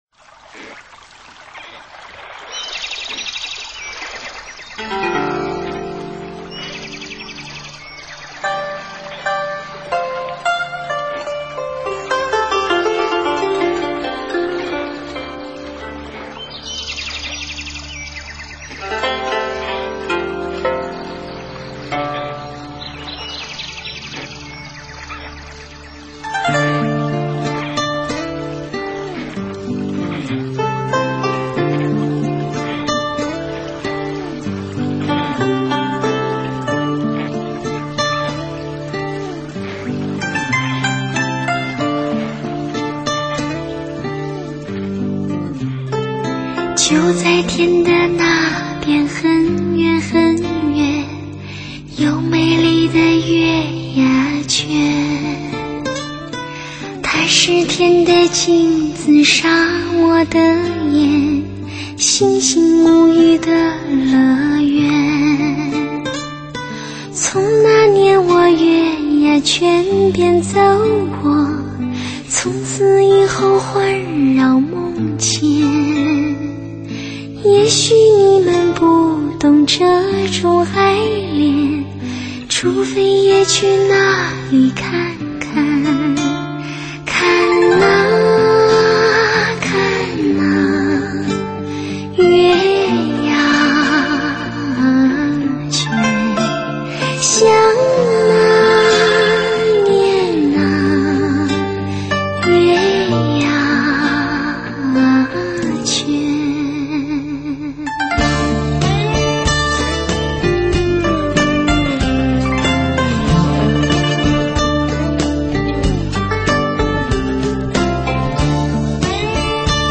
伊人靓声 声声醉人，十二把女声，纯净靓绝、圆润细
腻、自然而然的韵味，高水准的录音和制作，演唱魅力